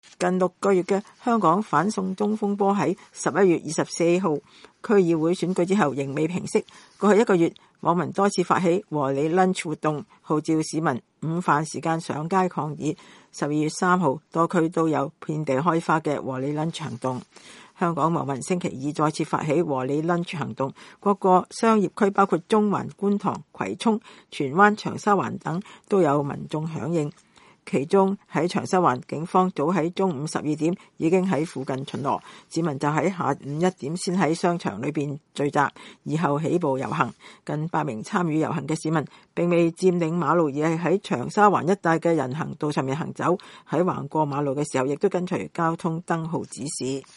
大部分參與遊行的市民都戴上口罩，高喊反修例口號。
有參與遊行的示威者在路過警方駐留的地方時高呼“解散警隊刻不容緩”。